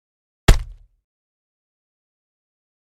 Звуки бронежилета: удар кулаком по броне